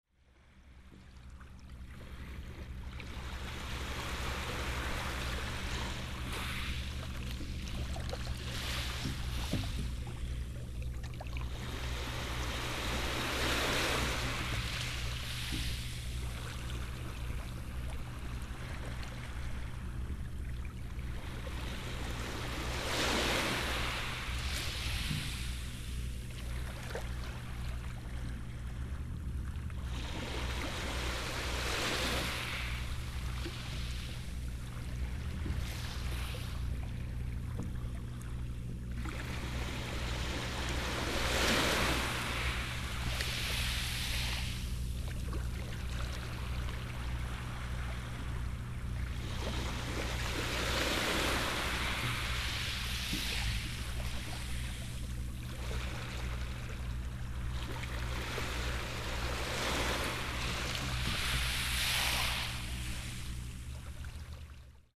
Field Recordings from Vietnam, Myanmar and Japan.
I heard a resonant sound coming from an old hallow pole on a beach.
Recorded on September 2007, Kehi no Matsubara, Japan.
hallow-pole-on-the-beach-september-2007-tsuruga-japan-excerpt.mp3